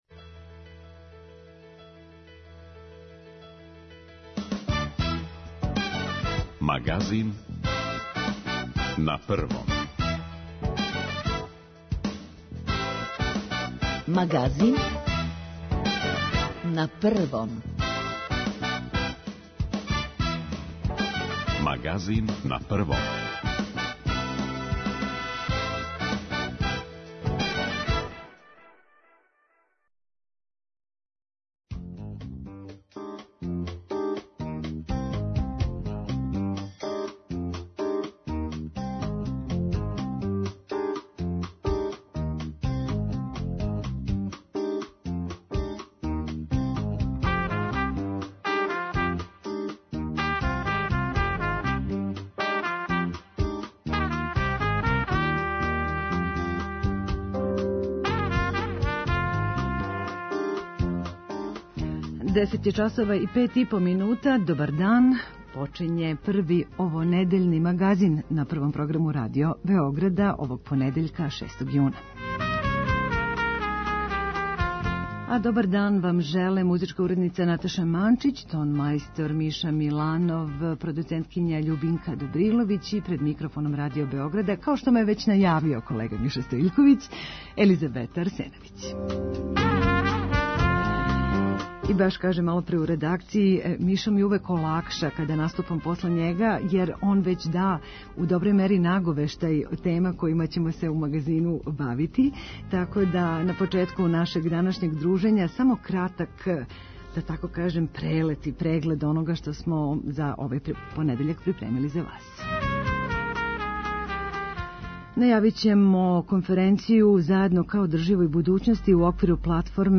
Ни овога месеца неће бити другачије него већ многих и многих за нама - централни сат 'Магазина на Првом' одвојен је за телефонска укључења слушалаца и њихове коментаре о нашем програму: примедбе, похвале, сугестије.